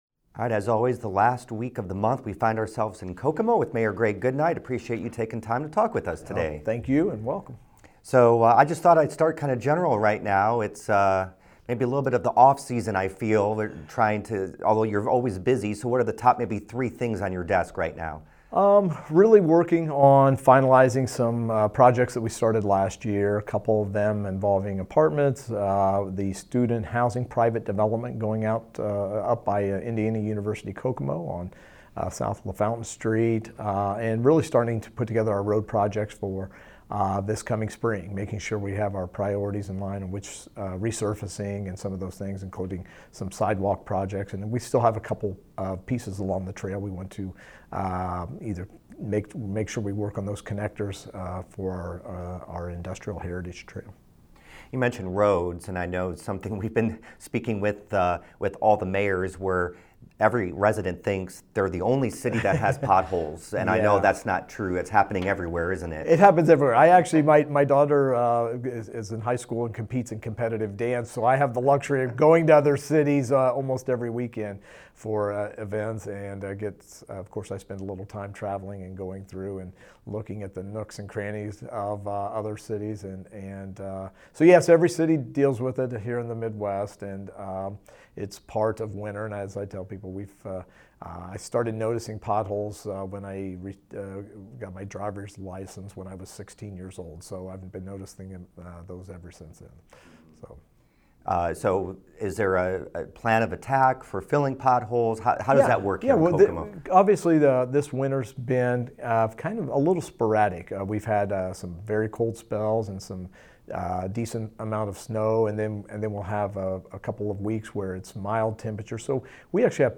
On this week’s installment of Ask The Mayor, Kokomo Mayor Greg Goodnight addresses these issues and more.